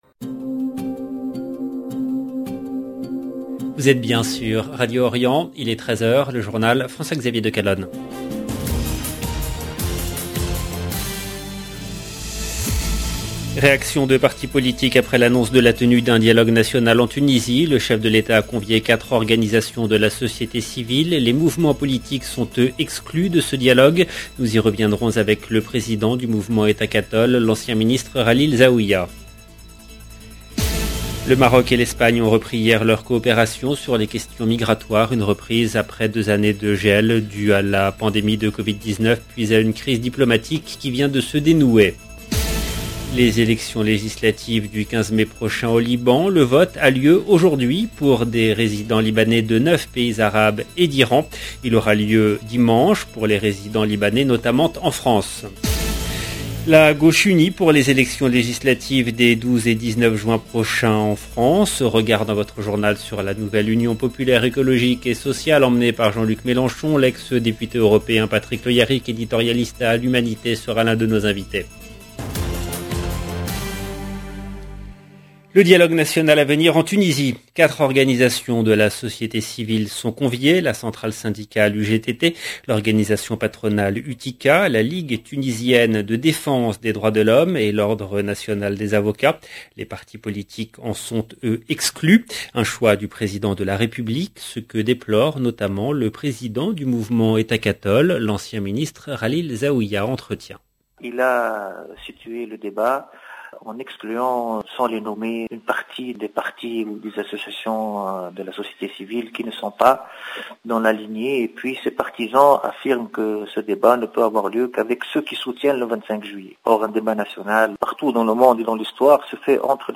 EDITION DU JOURNAL DE MIDI EN LANGUE FRANCAISE DU 6/5/2022